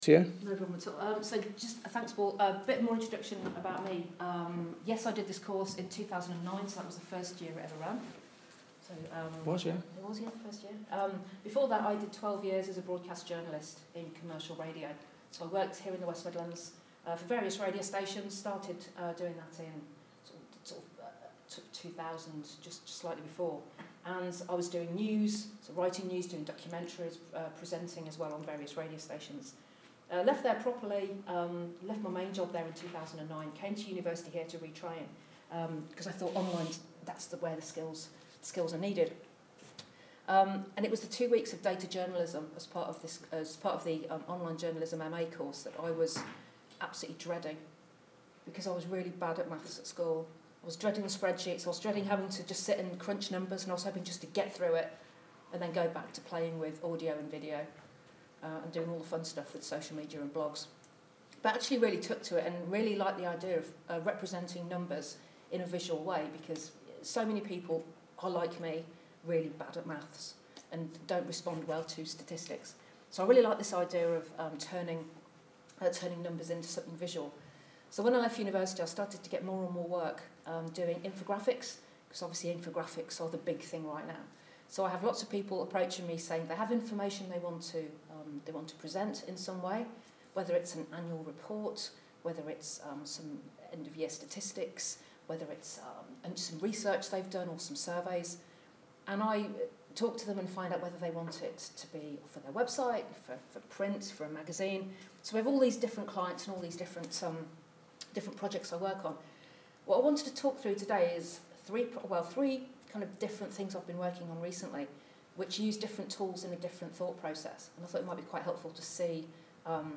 speaking to MA students